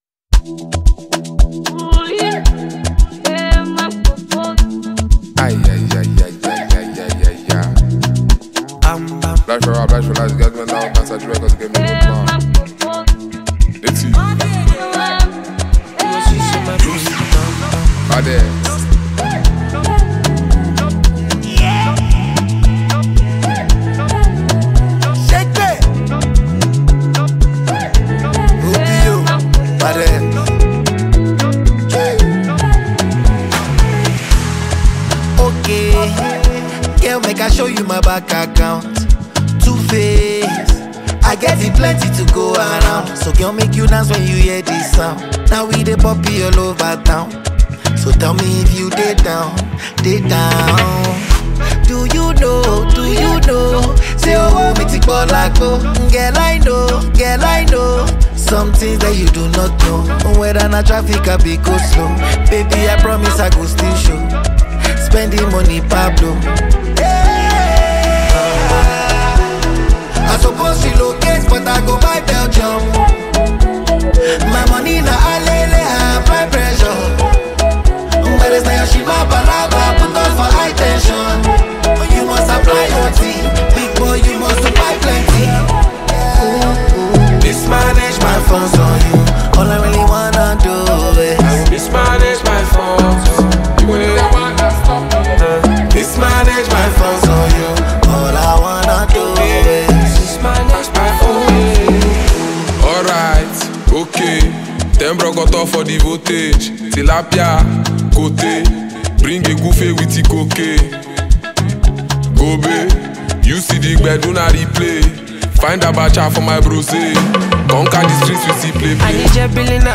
who deliver some fiery verses.